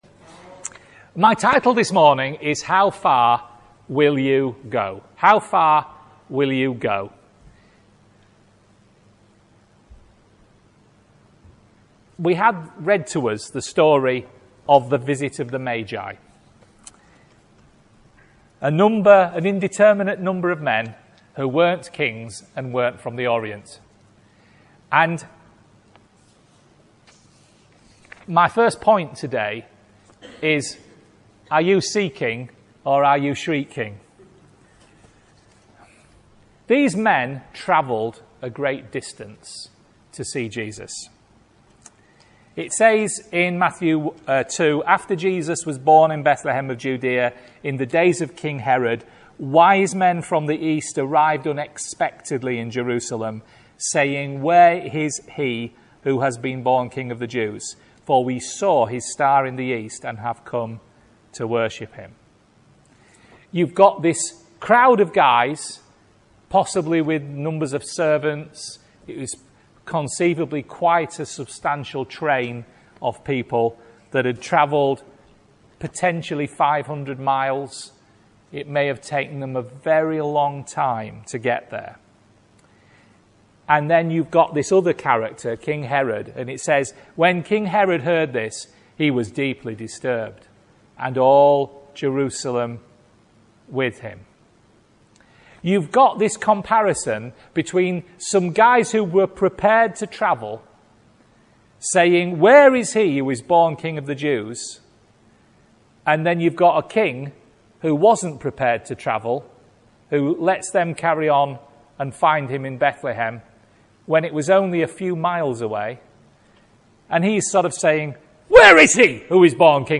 A Christmas Message : The wise men travelled far, possibly 500 miles, but how far will you go to meet with Jesus this Christmas?